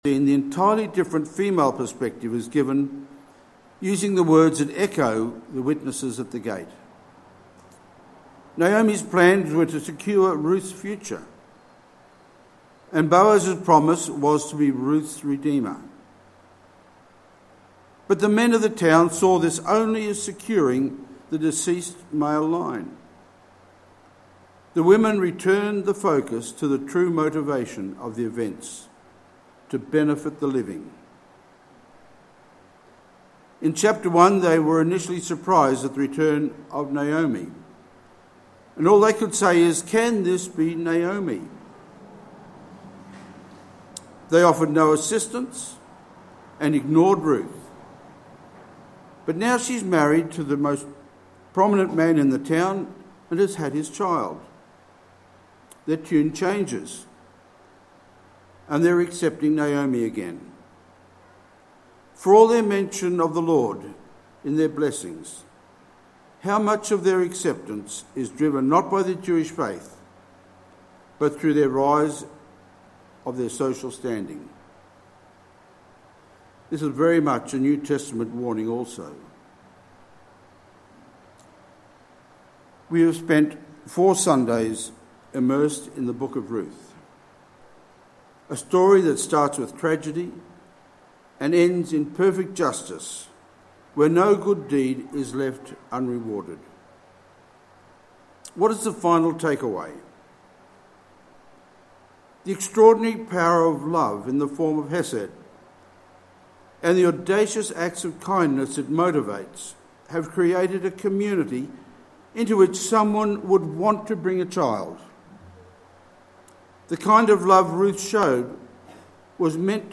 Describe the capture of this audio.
6/03/2022 Sunday service part 2